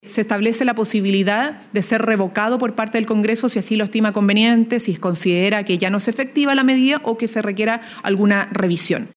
Consultada por La Radio, la ministra vocera de Gobierno, Camila Vallejo, confirmó el ingreso de la reforma constitucional y resaltó que no se considera saltarse al Congreso, ya que incluye una herramienta revocatoria.